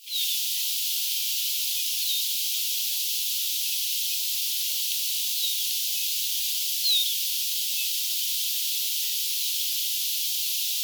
että ääni kuulostaa aika eriltä kuin havaintopaikalla.
lentoääntelyä hiukan
Vii vii vii tiiidy - tai jotain.
Siis aina tuollaisia väliääniä tiidy-äänten välillä oli.
tiidy-vihervarpusen_lentoaantelya_hiukan.mp3